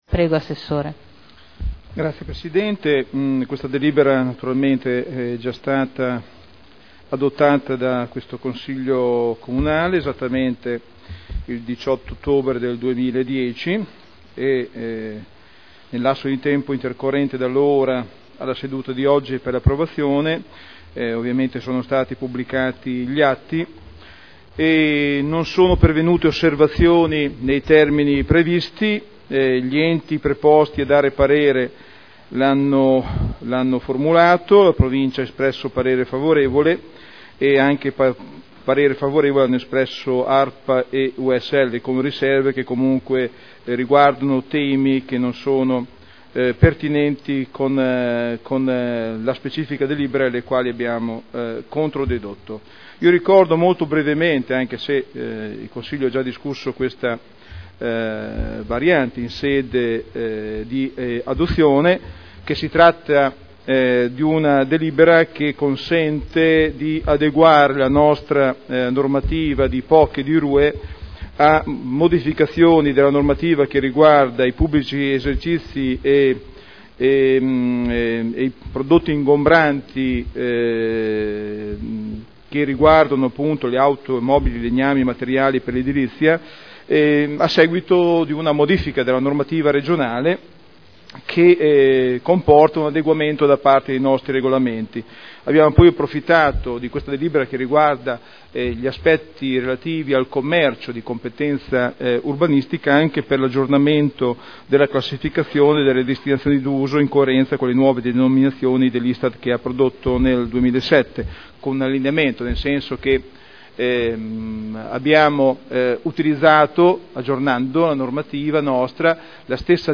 Seduta del 30/05/2011. Introduce proposta di deliberazione: Variante al POC e al RUE relativamente a pubblici esercizi e merceologie ingombranti, aggiornamento della disciplina degli immobili con codici ISTAR-ATECO 2002 – Approvazione